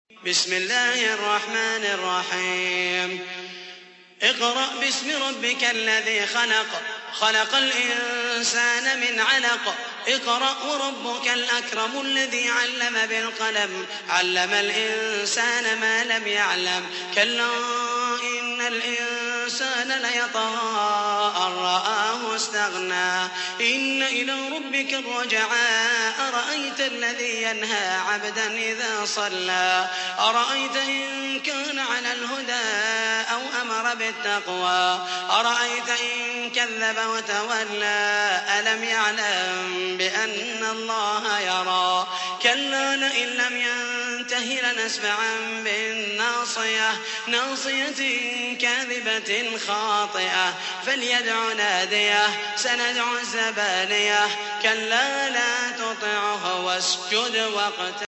تحميل : 96. سورة العلق / القارئ محمد المحيسني / القرآن الكريم / موقع يا حسين